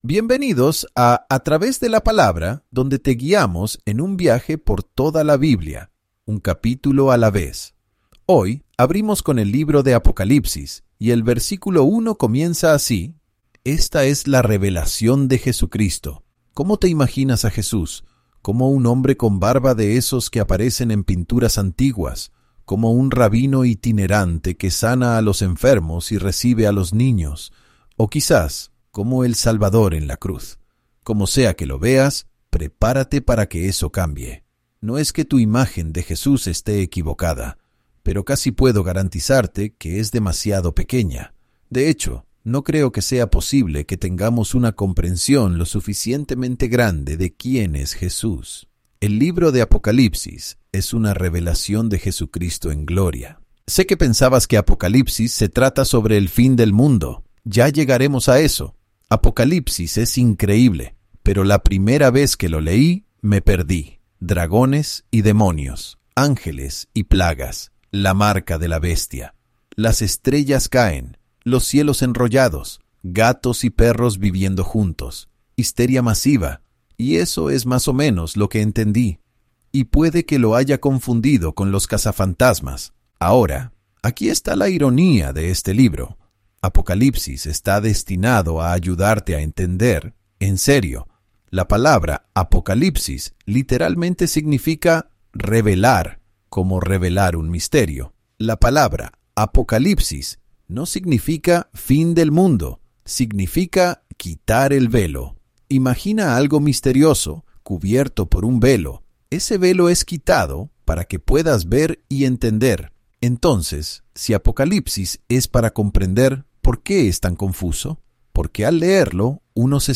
Para el beneficio de nuestros oyentes, TTW Bible Audio Guides (Guías de audio de “A Través de la Palabra”) utiliza tecnologías de IA para recrear las voces de los maestros en diferentes idiomas, con el apoyo de la supervisión humana y la garantía de calidad.